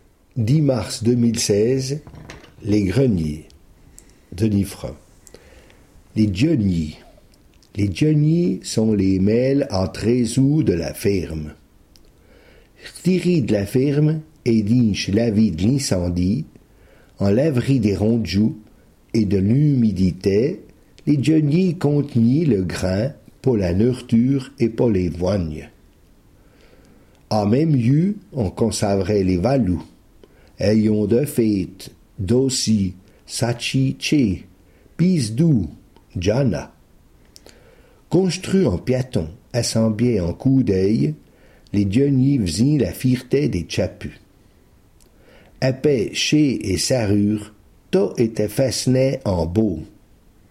Sommaire Ecouter le r�sum� en patois Panneau complet, consulter ou Situation ; coordonn�es (...)